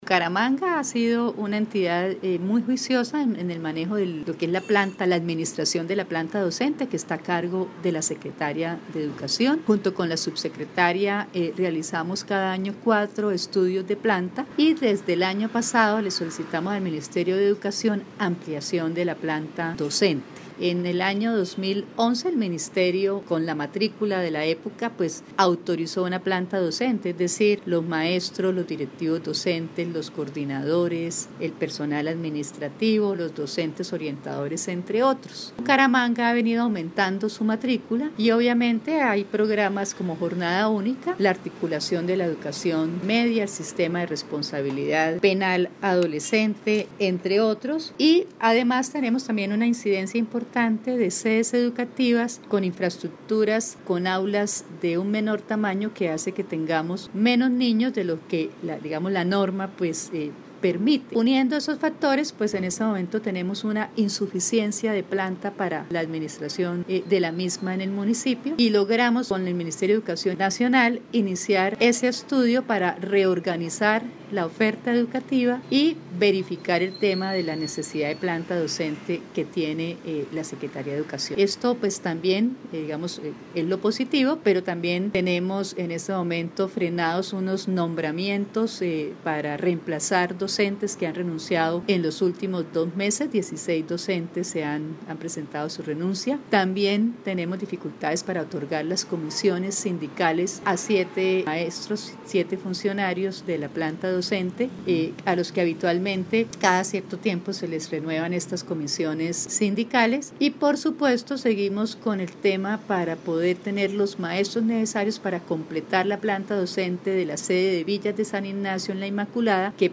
Ana Leonor Rueda Vivas, secretaria de Educación